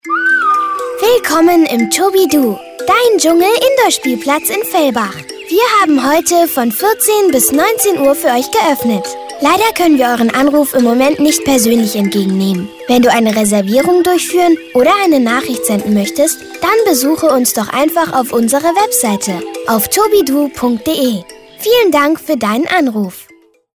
Telefonansage mit Kinderstimme
Auch wie süß… eine Telefonansage mit einer Kinderstimme.
Telefonansage Kinderstimme: